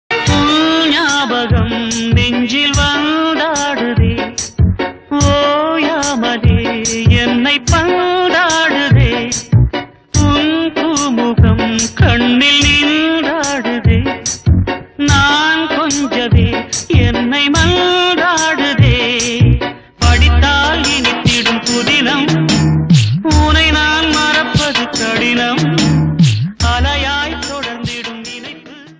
Música Clasica
CLASSICAL TAMIL LOVE CUT SONG 2019 MP3 RINGTONE